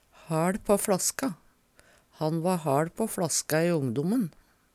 DIALEKTORD PÅ NORMERT NORSK haL på fLaska drikk mykje av det sterke slaget Eksempel på bruk Han va haL på fLaska i ongdommen.